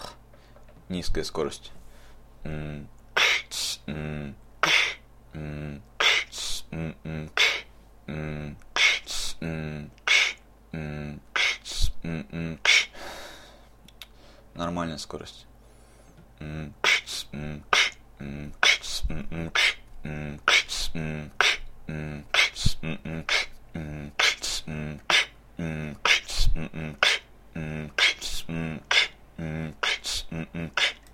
Возможно интересный бит для новичков
Mmm kchtss mmm kch
Mmm kchtss m m kch